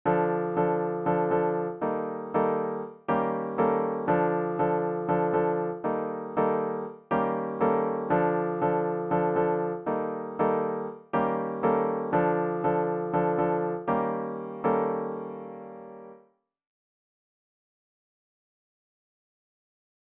マイナーキーになってもリズムやコードの形はぼ同じです。